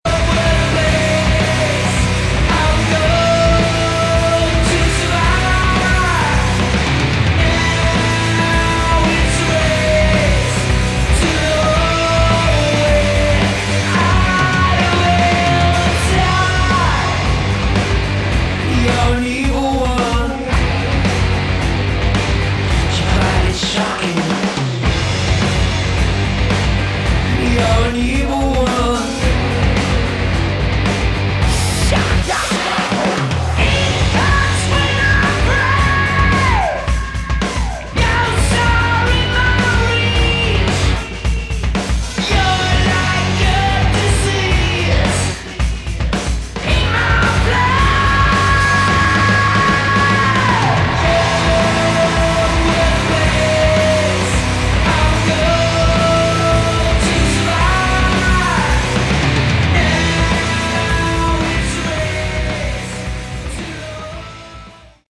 Category: Sleazy Hard Rock
vocals
electric & acoustic guitars, Mellotron
bass
drums (studio), strings